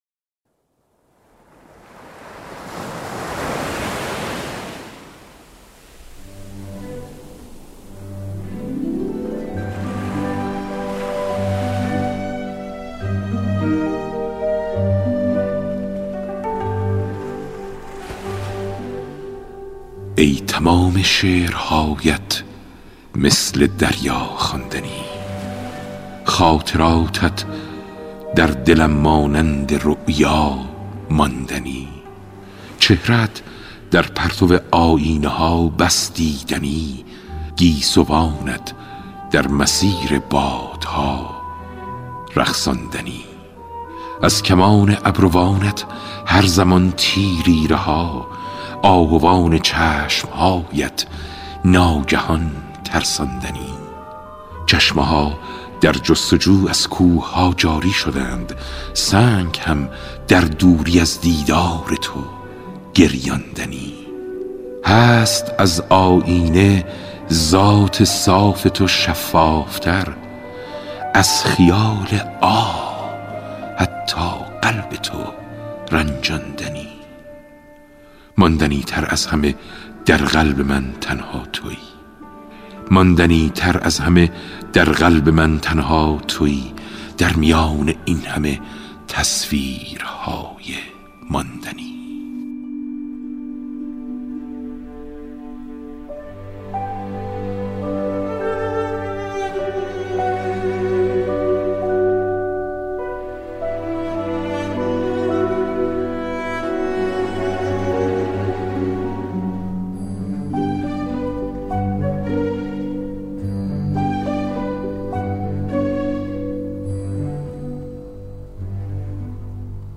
گفتگوی رادیویی مهران دوستی با «هوشنگ مرادی کرمانی»
توانمندی او در اجرا صدای ششدانگ و شفاف، انعطاف صدا و آگاهی او در زمینه‌های هنری باعث شده بود که مهران دوستی گوینده‌ای کم نظیر باشد و خاطره‌های بسیاری برای مردم این سرزمین بسازد.